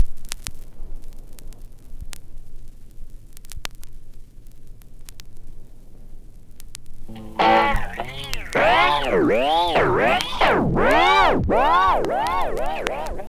GTR1FX    -L.wav